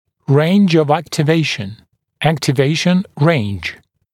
[reɪnʤ əv ˌæktɪ’veɪʃn] [ˌæktɪ’veɪʃn reɪnʤ][рэйндж ов ˌэкти’вэйшн] [ˌэкти’вэйшн рэйндж]диапазон активации